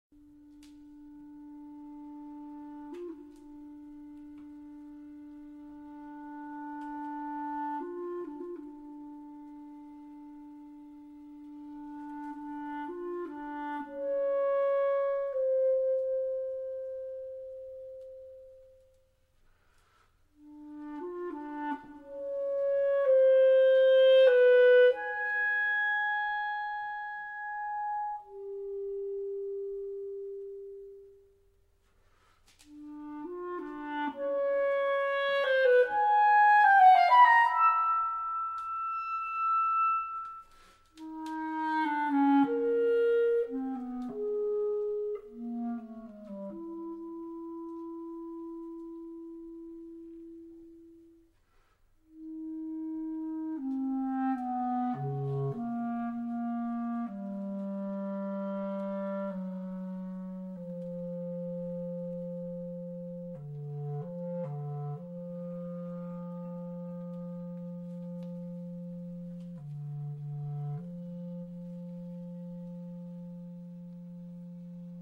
CLARINETE (viento madera)